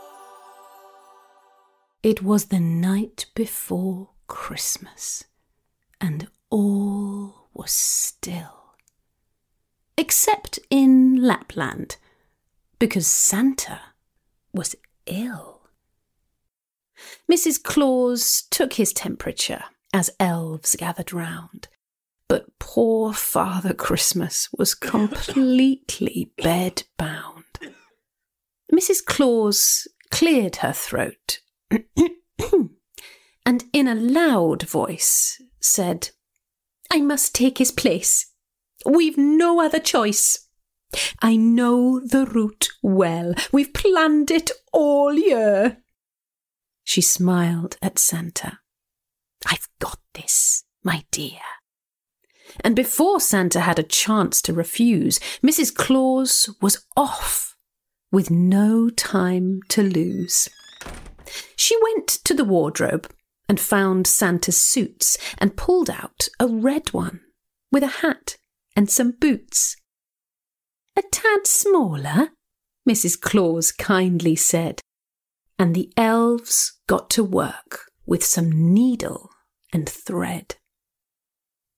Mrs claus christmas audiobook